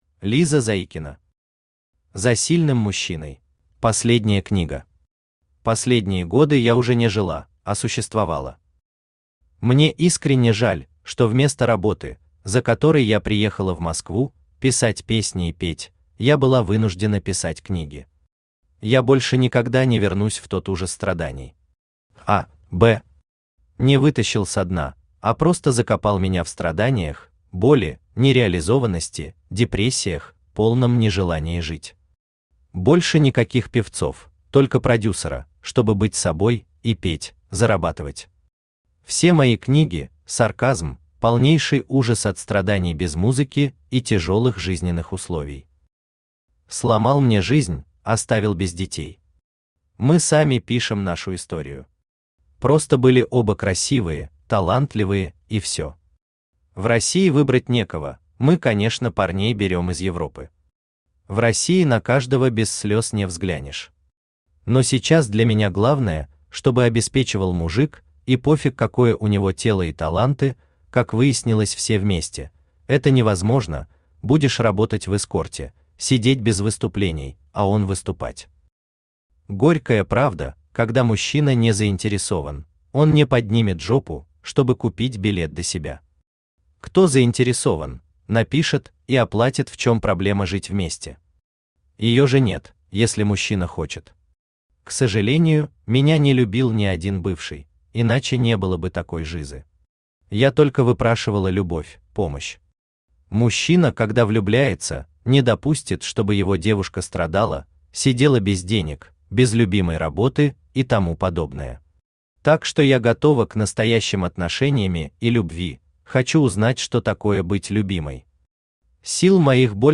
Аудиокнига За сильным мужчиной | Библиотека аудиокниг